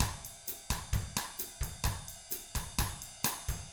129BOSSAF3-R.wav